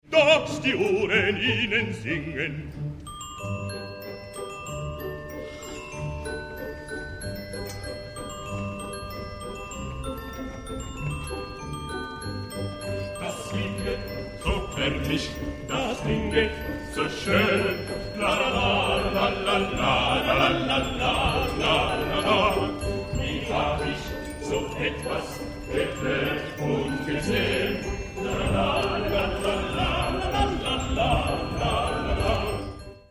Teatro della Pergola, Firenze 1993 Orchestra del Maggio Musicale Fiorentino direttore Zubin Mehta (Glockenspiel